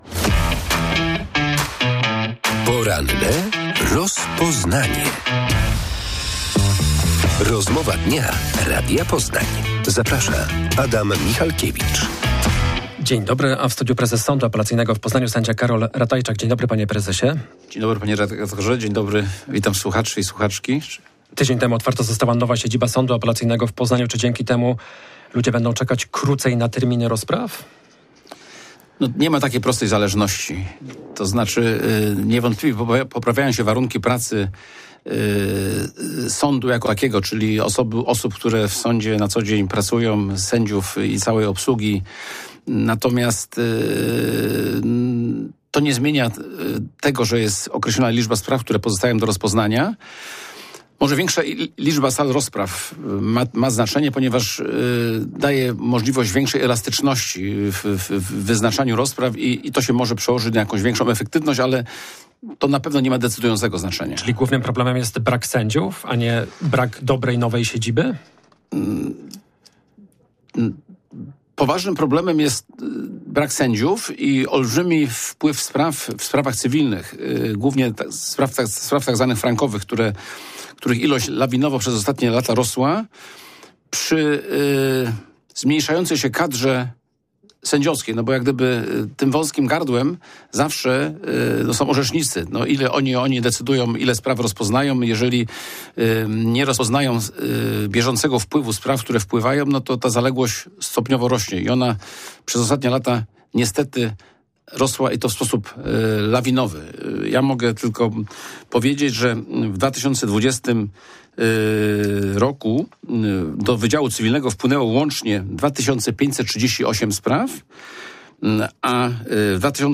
W porannej rozmowie Radia Poznań prezes sądu Karol Ratajczak poinformował, że wpłynął do niego wniosek o zwołanie zgromadzenia ogólnego sędziów.